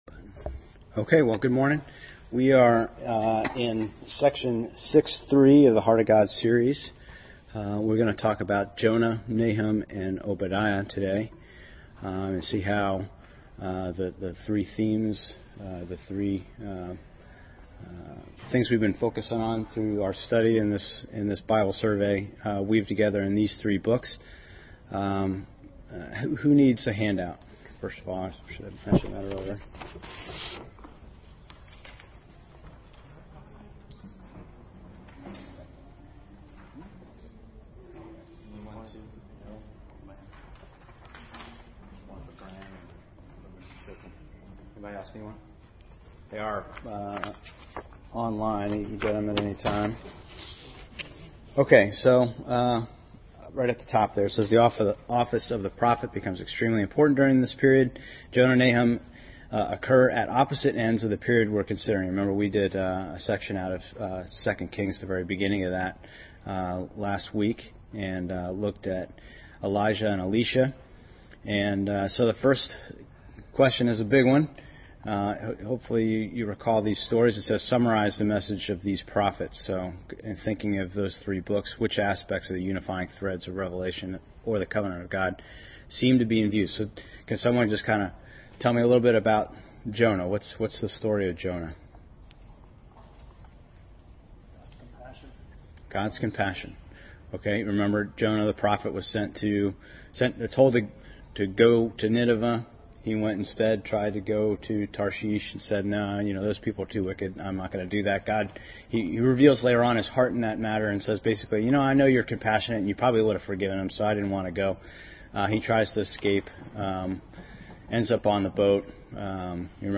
The series covers multiple Sunday School periods.